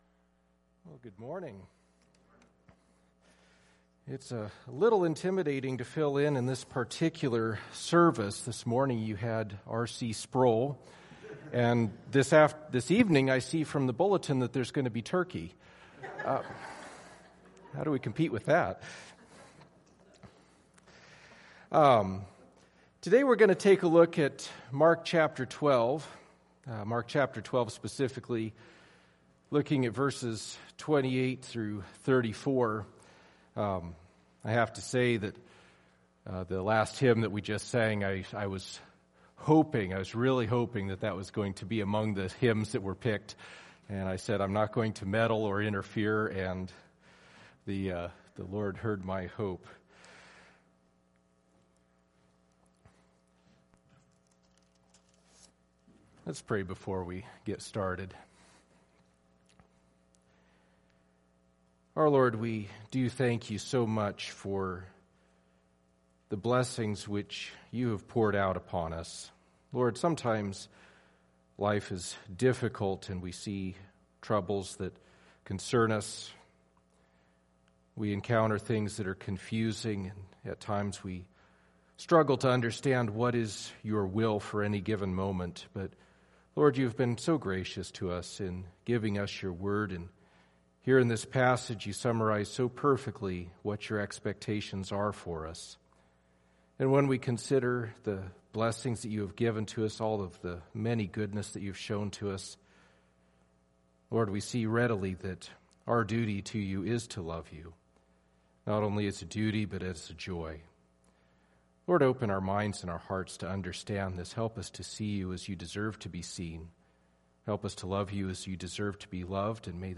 Matthew 12:28-34 Service Type: Sunday Morning Topics